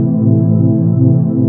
IKO PAD.wav